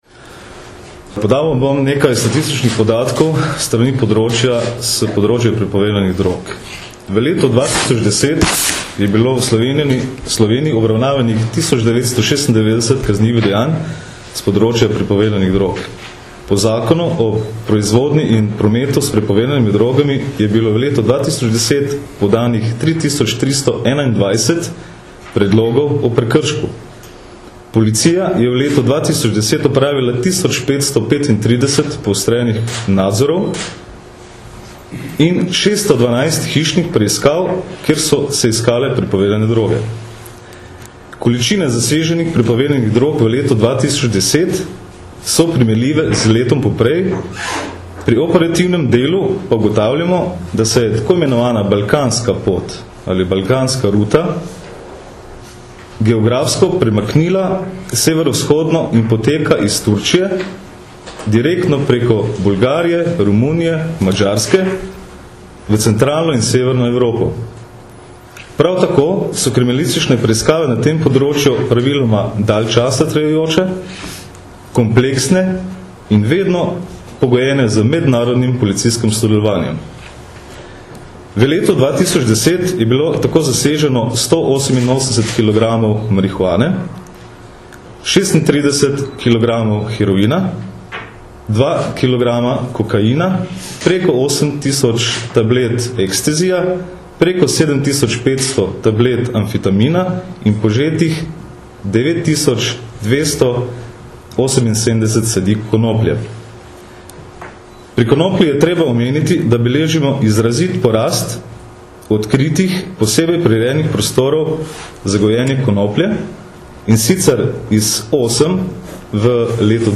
Na današnji novinarski konferenci smo podrobneje predstavili sedem najnovejših uspešno zaključenih kriminalističnih preiskav na področju prepovedanih drog ter spregovorili o problematiki njihovega gojenja in uživanja.
Zvočni posnetek izjave